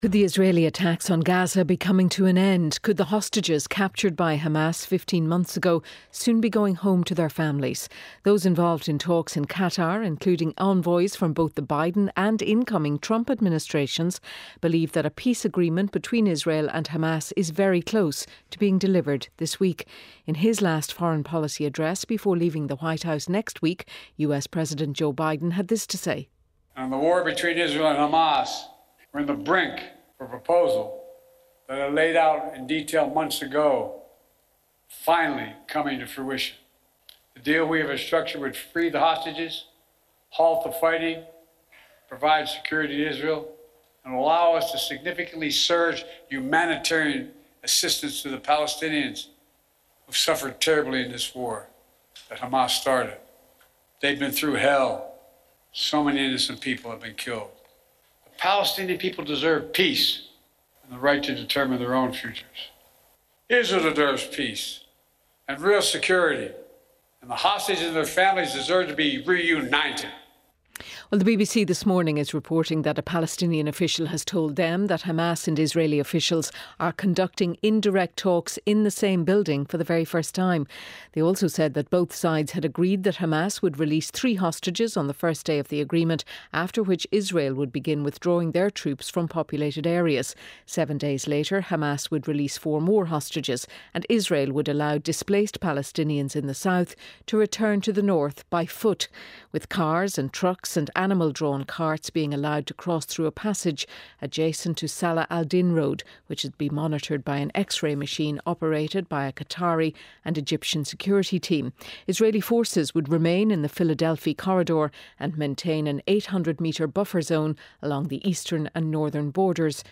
Gideon Levy, Israeli journalist and columnist with Haaretz, discusses the likelihood of a peace deal in Gaza and release of some hostages.